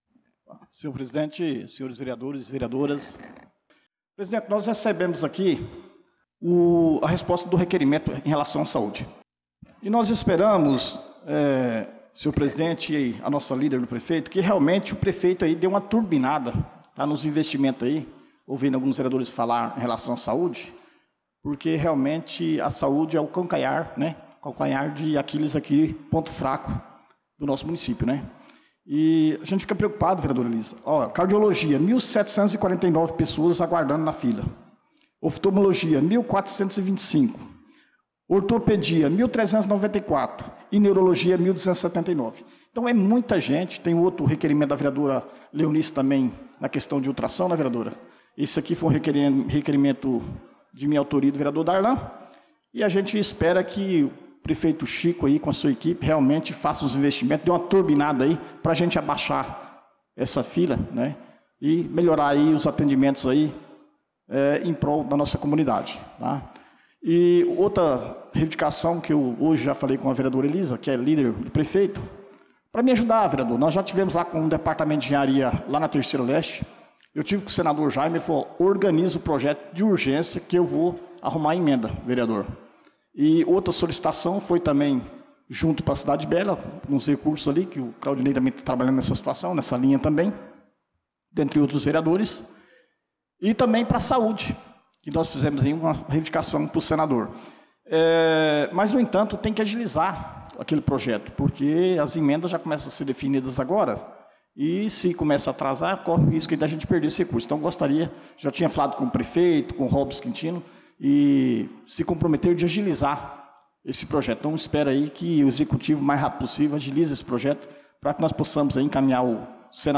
Pronunciamento do vereador Dida Pires na Sessão Ordinária do dia 12/05/2025